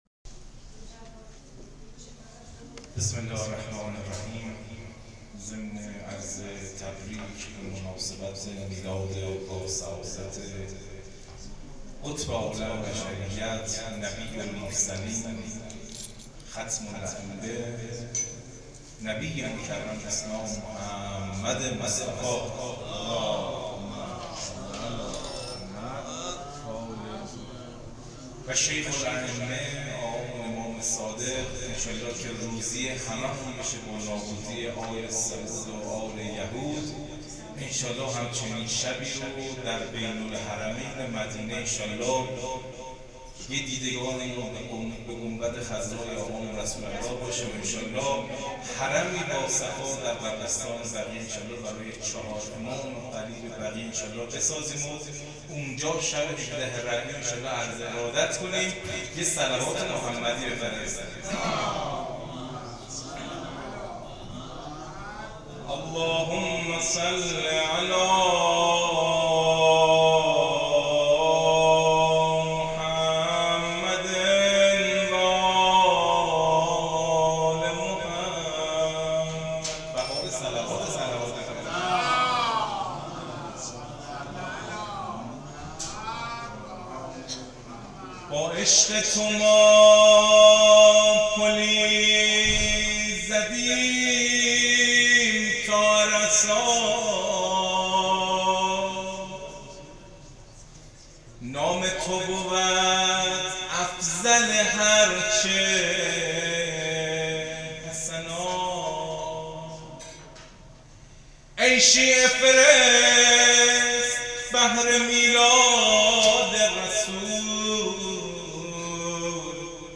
جشن میلاد پیامبر اسلام ص